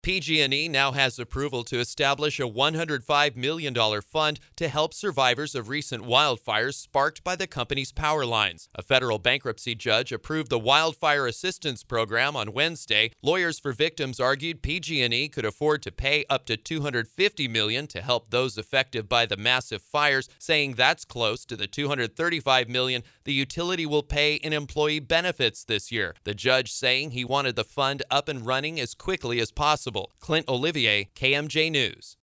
reports.